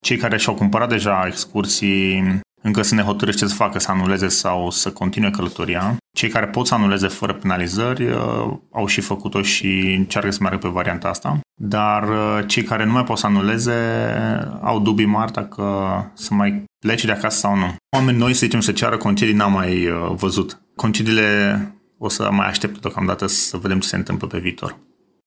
operator turism